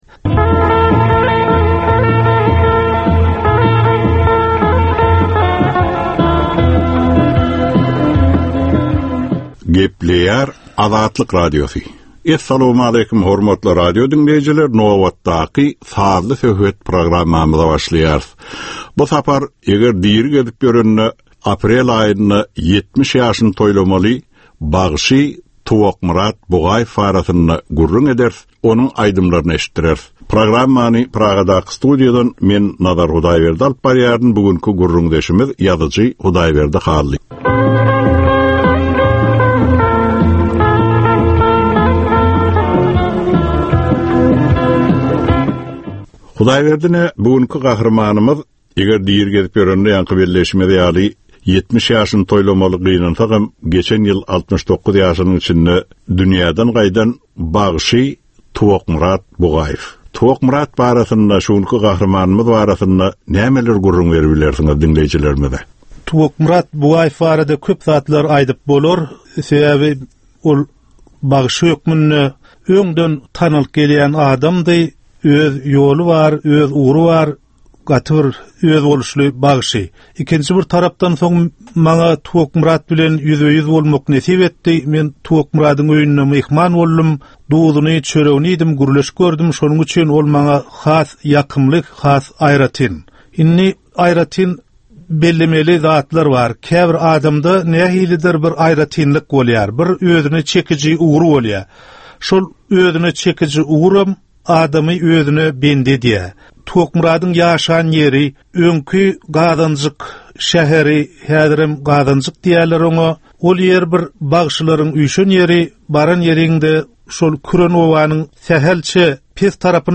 Türkmenistanyň käbir aktual meseleleri barada sazly-informasion programma.